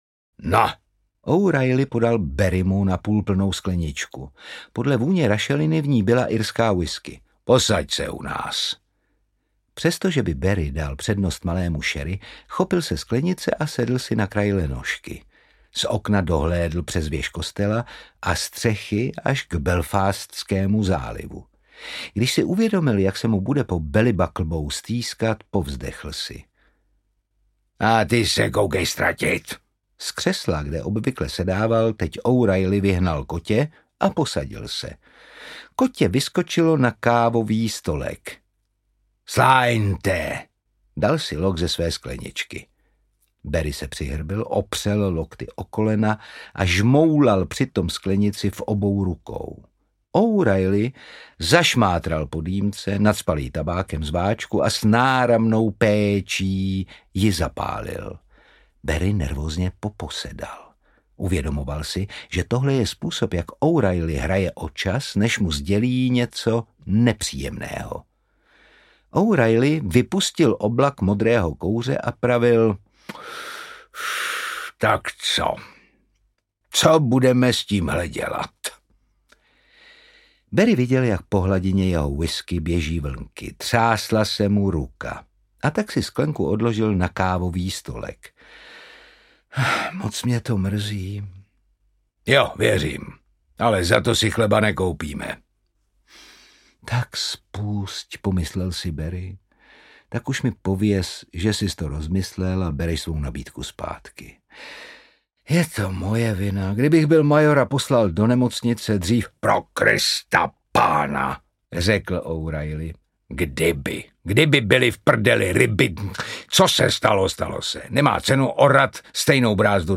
Doktore, teď to chce klid! audiokniha
Ukázka z knihy
Čte Otakar Brousek.
Vyrobilo studio Soundguru.
• InterpretOtakar Brousek ml.